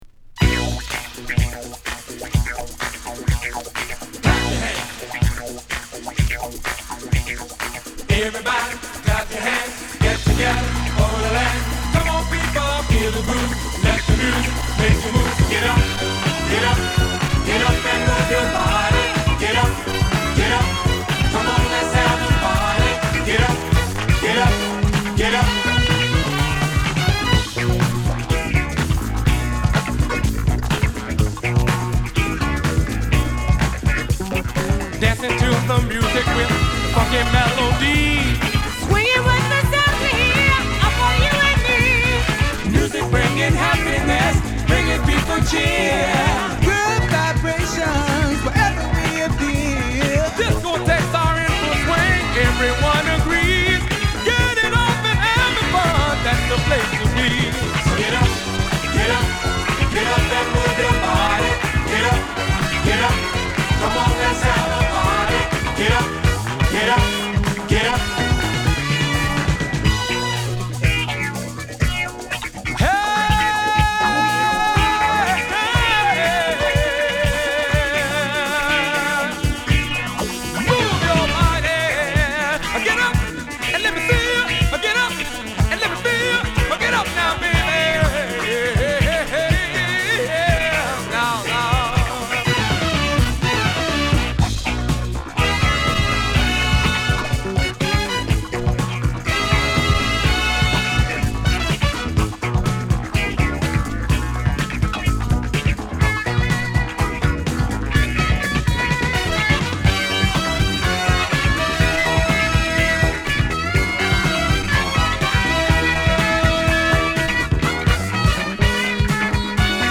パワフルなビートとベースにファンキーなギターやシンセが絡むファンキーディスコチューン
弾んだドラミングのディスコトラックにソウルフルな女性ヴォーカルが乗る